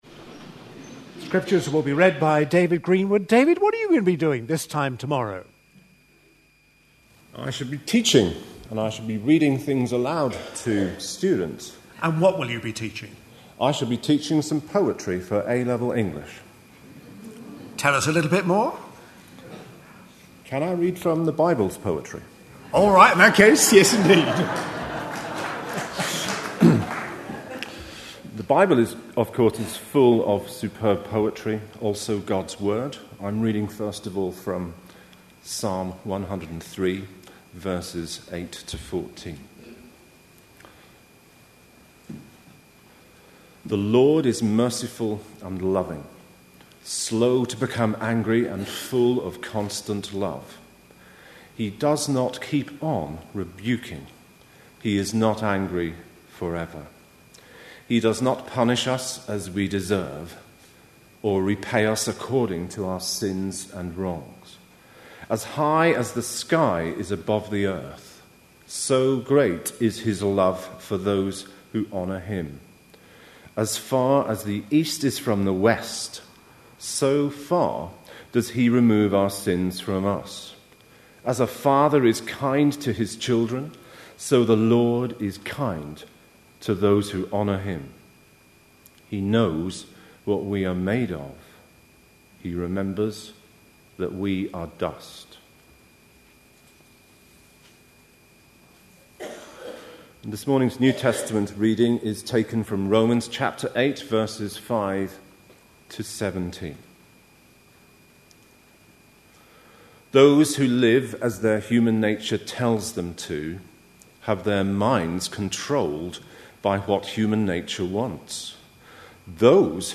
A sermon preached on 29th April, 2012, as part of our Life in The Spirit series.